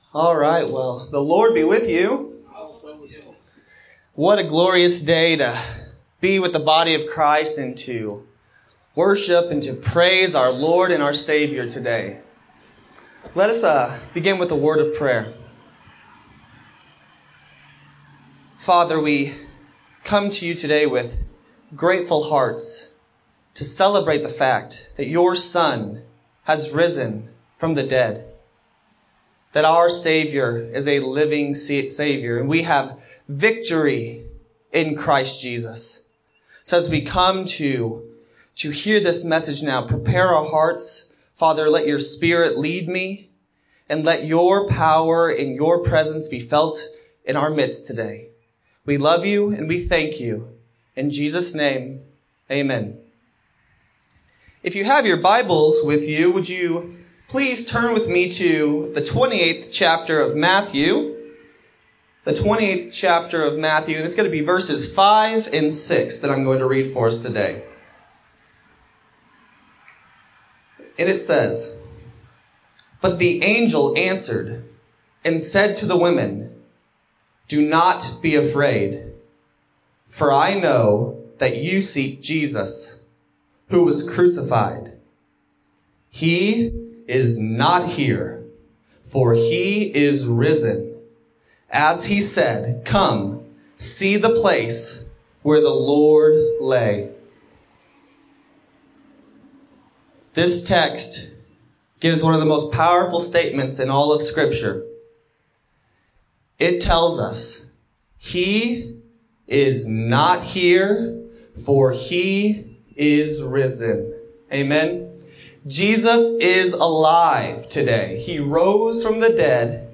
Easter Service
4-12-20-Sermon-CD.mp3